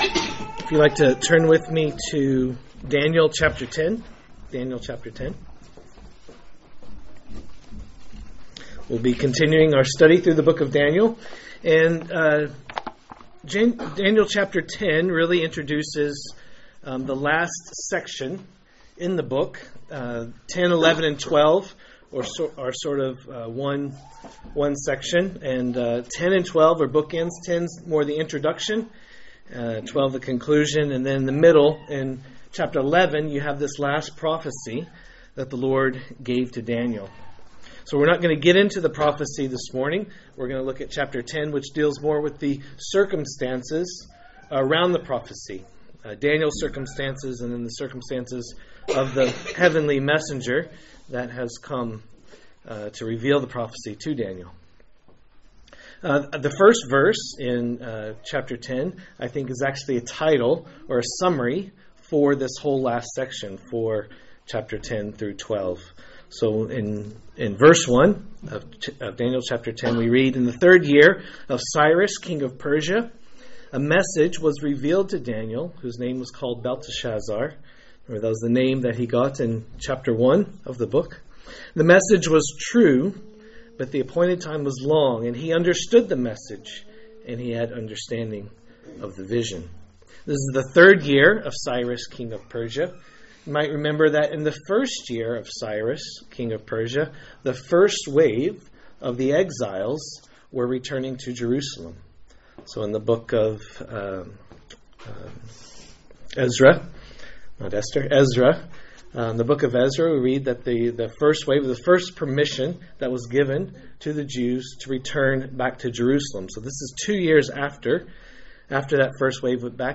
A message from the series "Daniel." Daniel 10